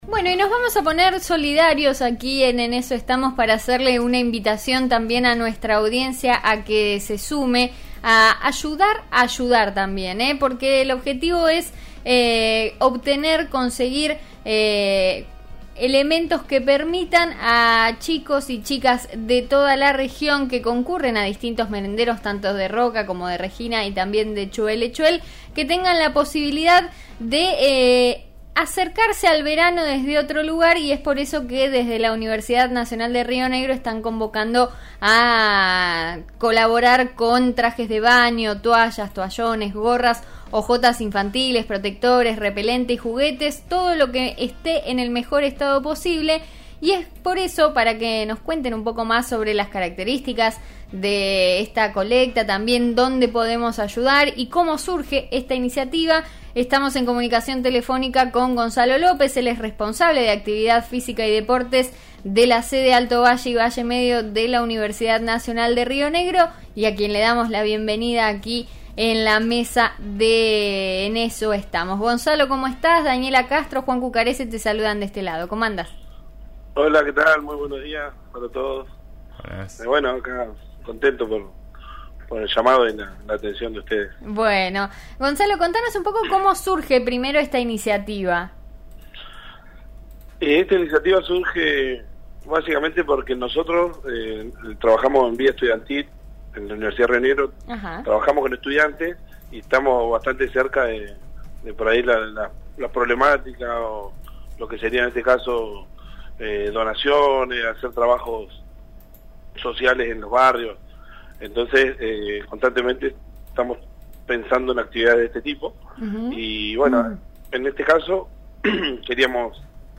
contó al aire de «En Eso Estamos» de RN RADIO (89.3) los detalles de la iniciativa, que incluirá a las comunidades de Roca, Regina y Choele Choel.